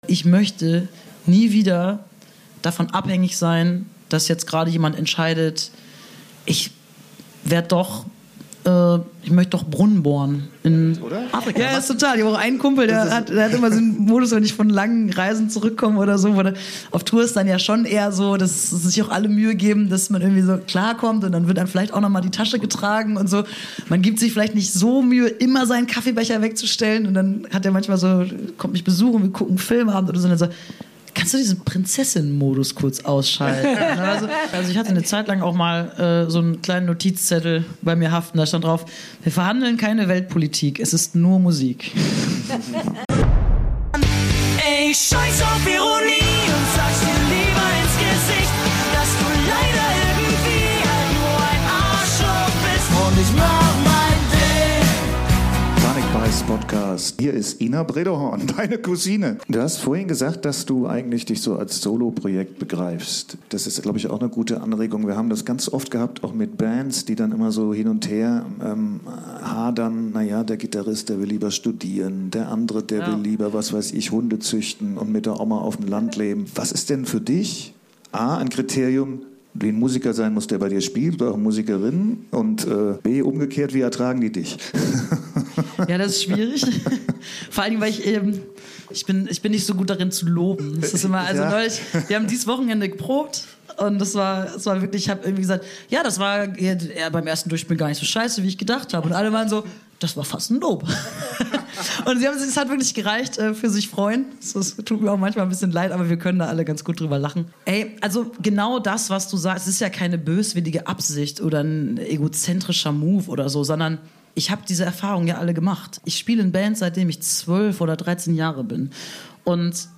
Aus der "Alten Liebe" in Hamburg St. Pauli.